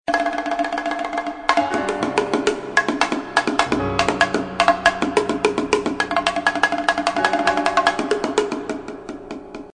barabany_24715.mp3